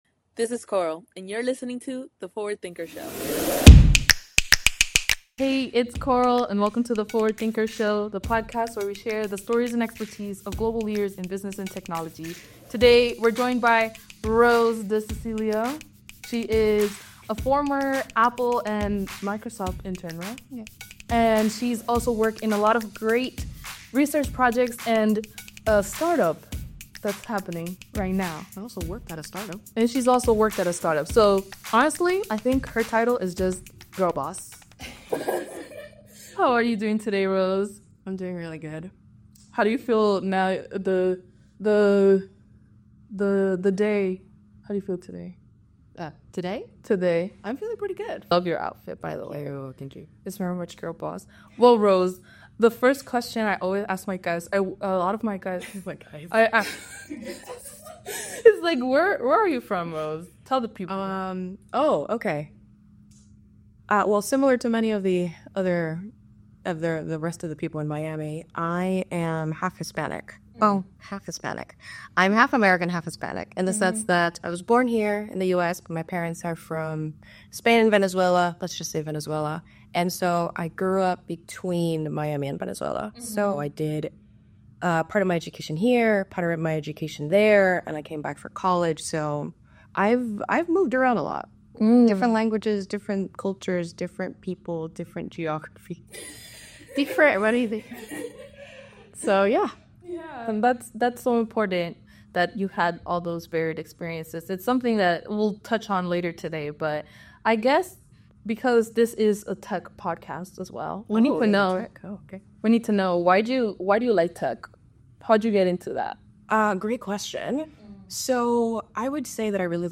Featuring conversations with forward-thinking leaders from diverse backgrounds, the podcast captures unique insights and groundbreaking ideas.